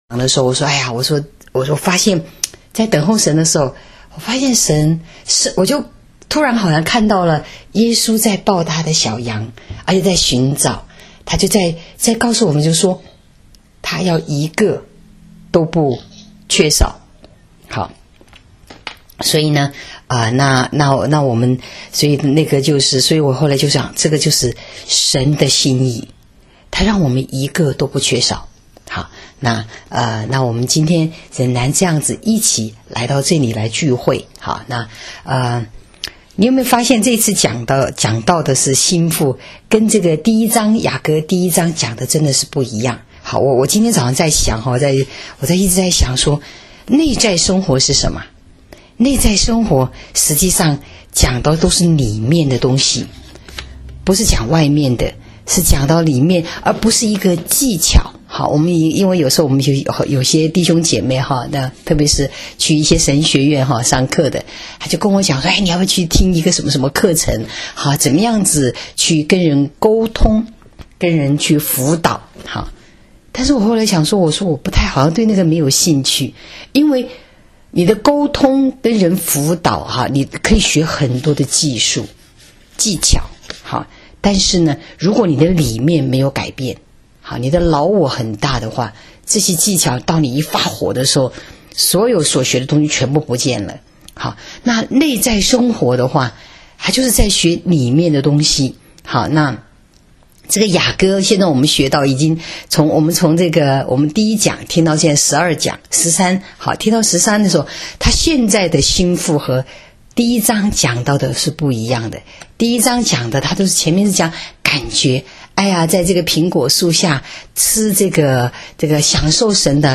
这次的周六聚会，我们是第一次用现场转播的形式，将QT房间和YY频道连在一起聚会。